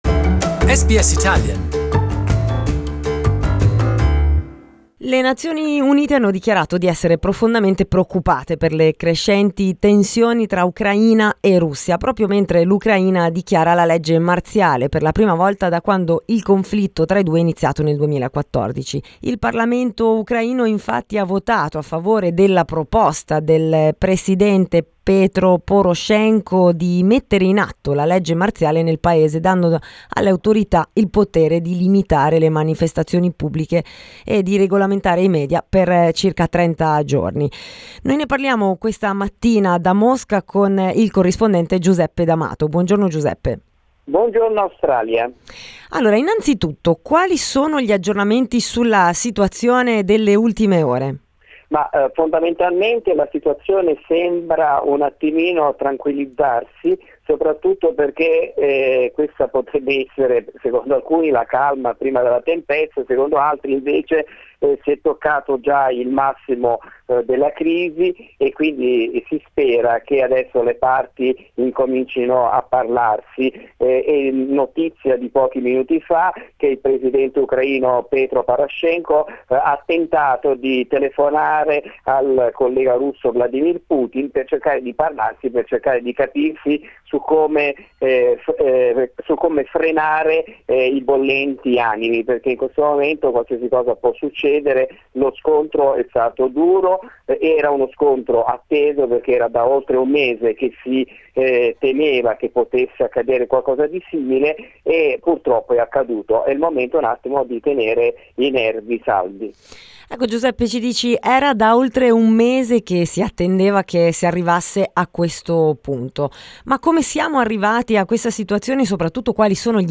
While many in the international community are criticising Russia for using open military force, an Australian analyst suggests Ukraine could actually benefit internally from the worsening conflict. We talked about the issues with Moscow correspondent